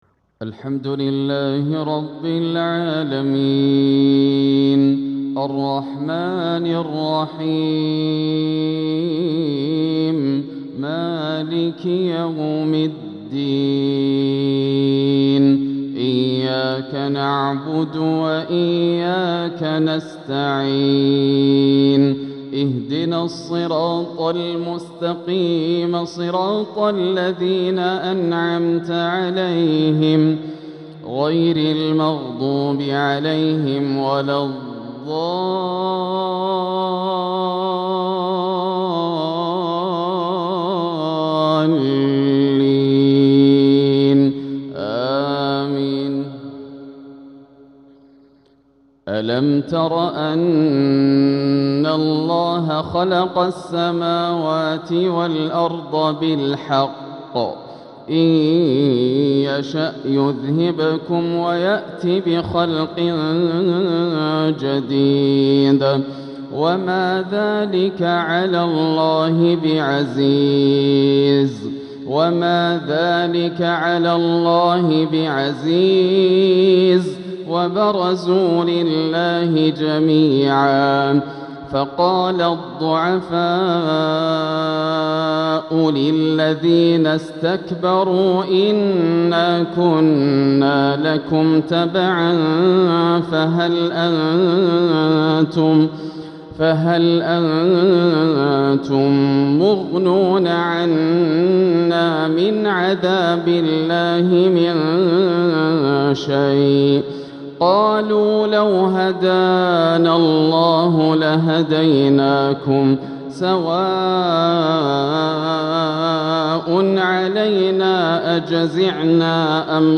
تلاوة شجيَّة من سورة إبراهيم | عشاء الأربعاء 5-2-1447هـ > عام 1447 > الفروض - تلاوات ياسر الدوسري